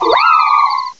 cry_not_leafeon.aif